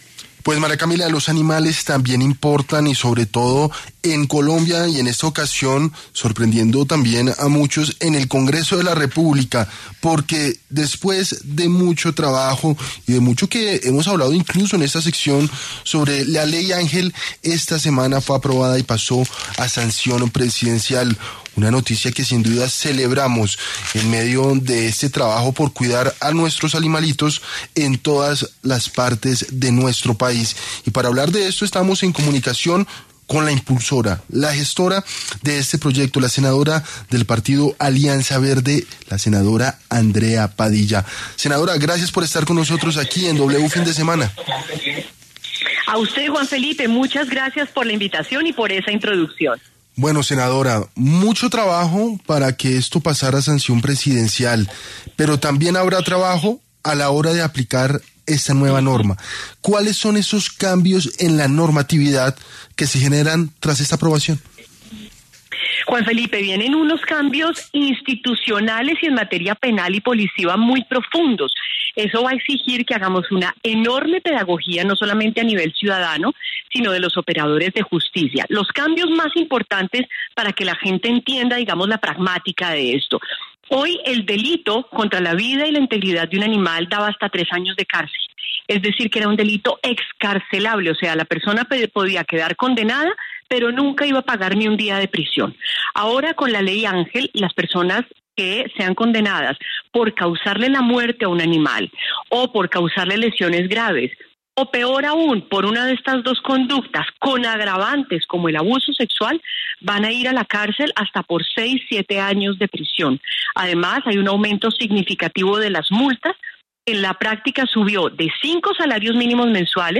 La congresista Andrea Padilla explicó en W Fin de Semana los detalles de la Ley Ángel, que endurece las penas contra maltratadores de animales.